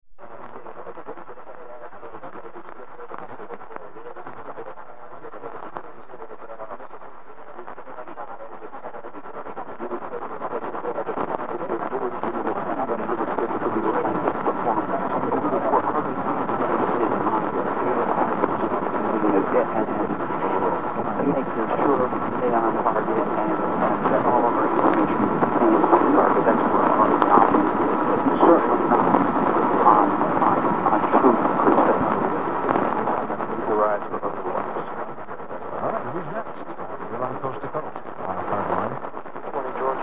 This recording was taken directly from the radio's headphone output